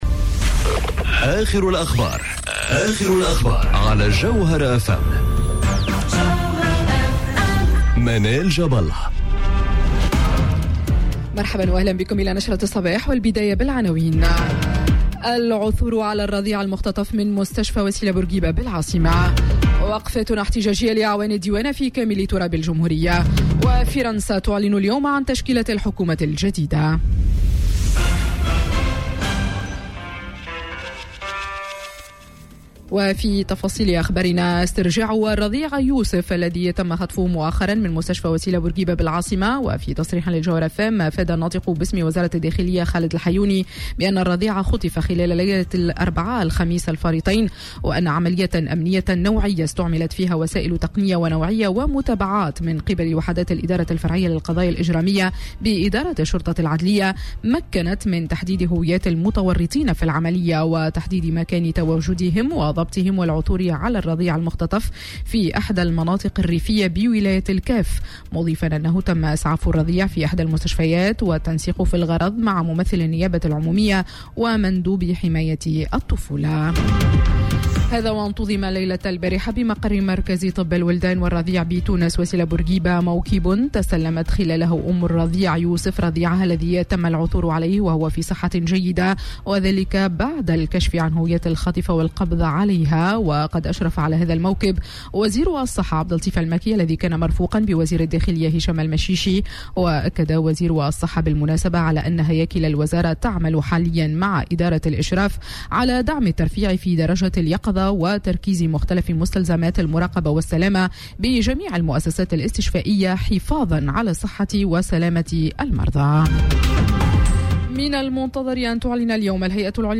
نشرة أخبار السابعة صباحا ليوم الإثنين 06 جويلية 2020